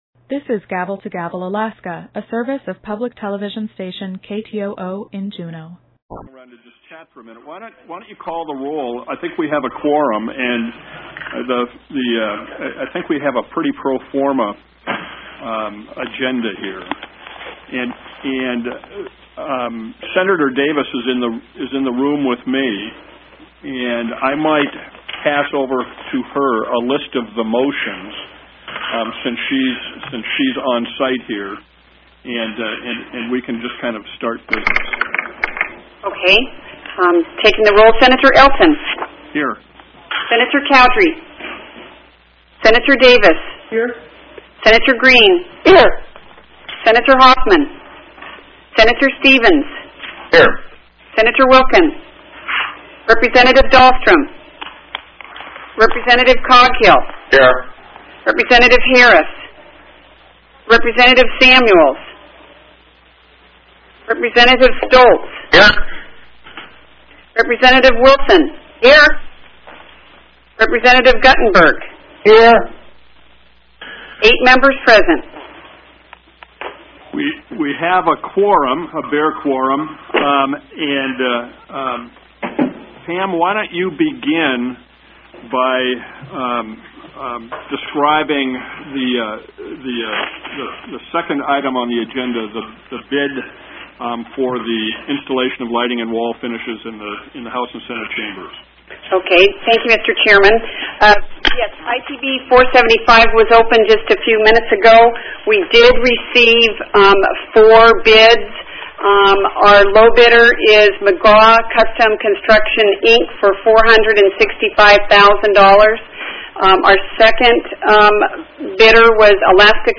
-- Teleconference --